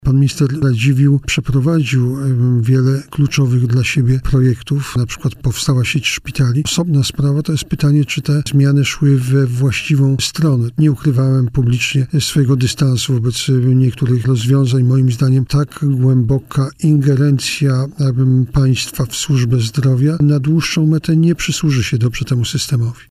Premier Gowin ocenił na antenie Radia Warszawa działania ministra zdrowia Konstantego Radziwiłła.